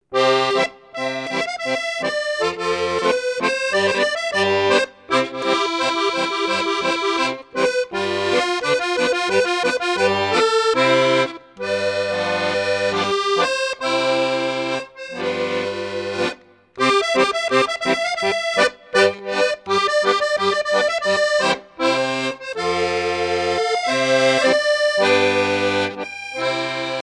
danse : paskovia
Pièce musicale éditée